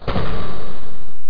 DOOROPEN.mp3